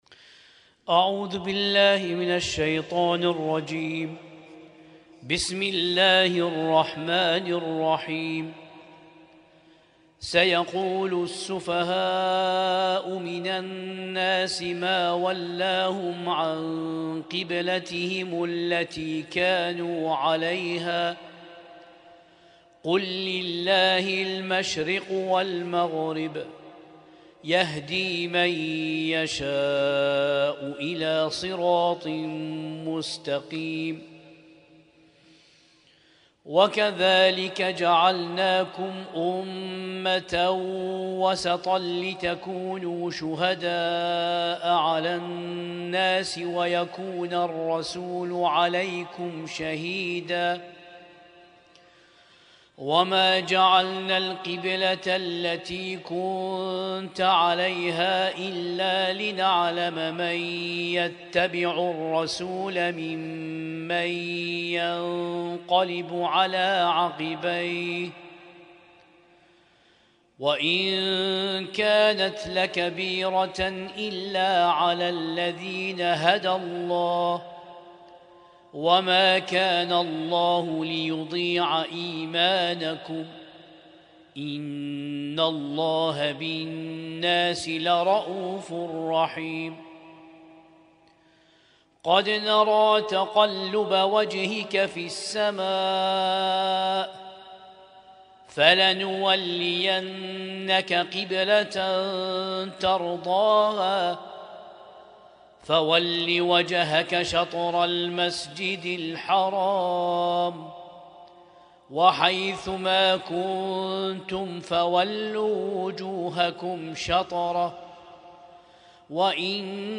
القارئ
ليلة 2 من شهر رمضان المبارك 1447هـ